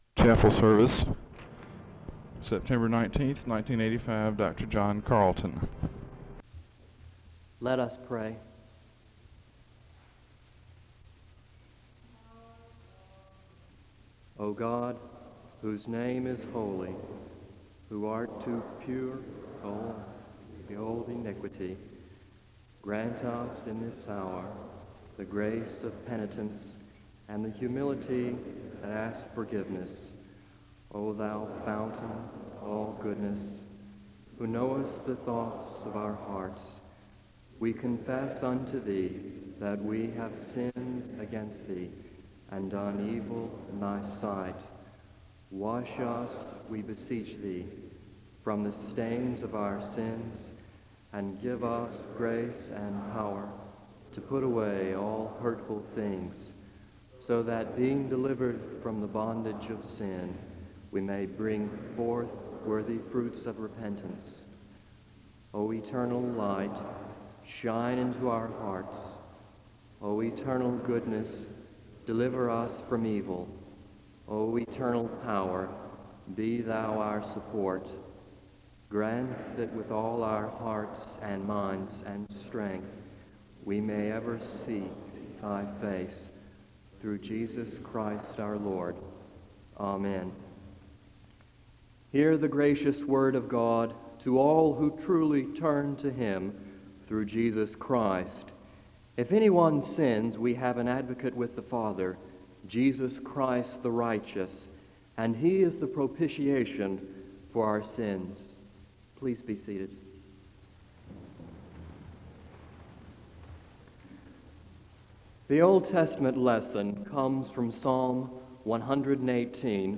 The service begins with a prayer (0:00-1:32).
A blessing is given (17:00-17:20).
SEBTS Chapel and Special Event Recordings SEBTS Chapel and Special Event Recordings